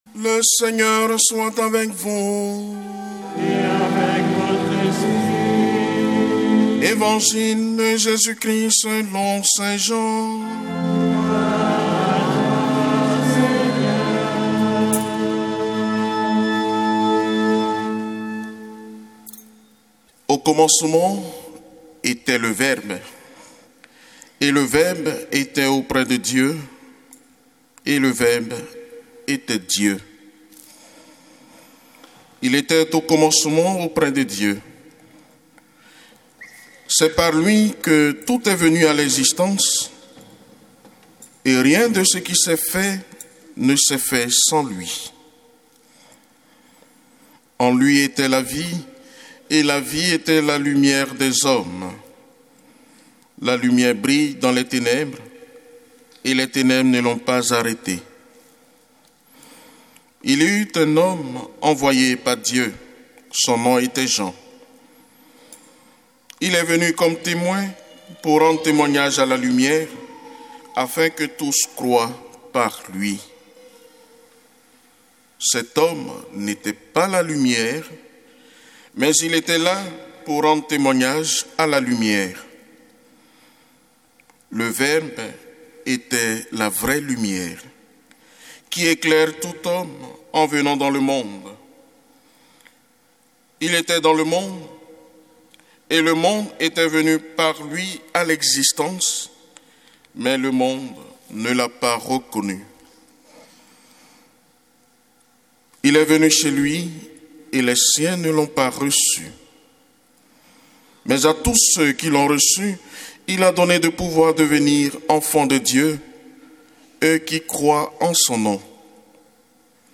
Évangile de Jésus Christ selon saint Jean avec l'homélie